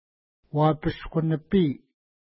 Uapashku-nipi Next name Previous name Image Not Available ID: 367 Longitude: -59.4967 Latitude: 54.5413 Pronunciation: wa:pisku-nəpi: Translation: White Bear Lake Official Name: White Bear Lake Feature: lake